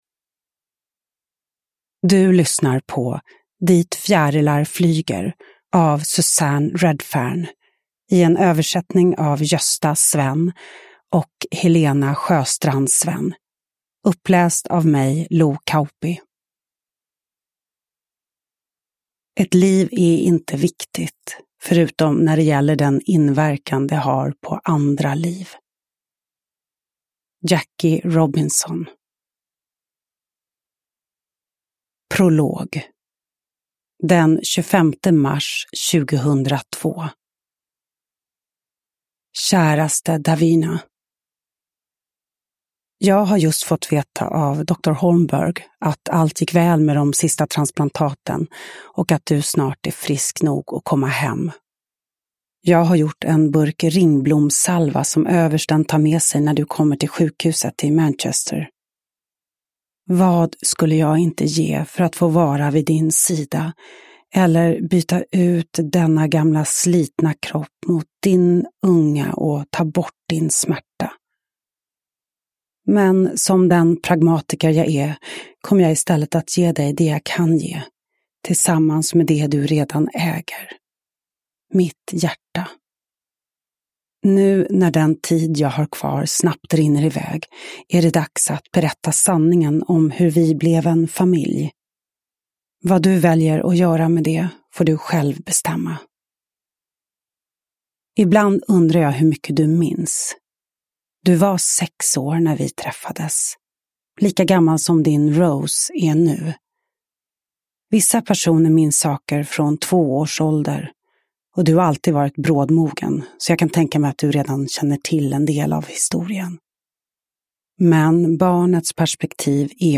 Uppläsare: Lo Kauppi
Ljudbok